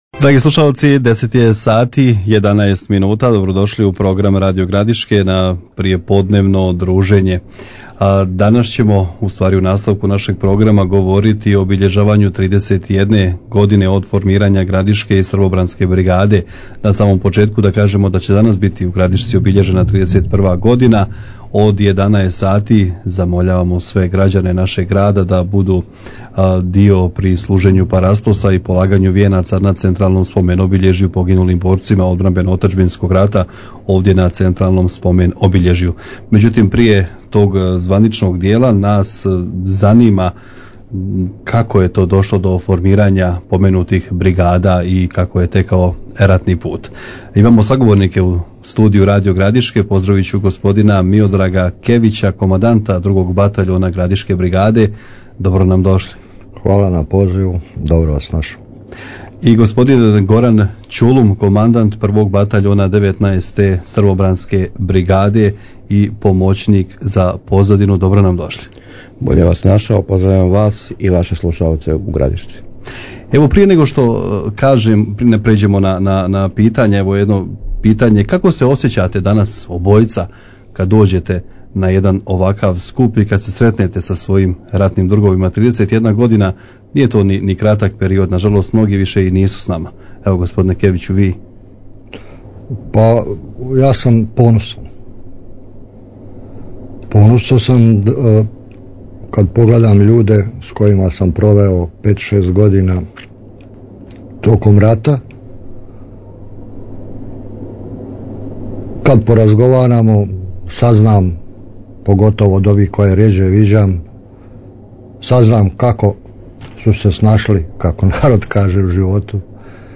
гостовали су у програму Радио Градишке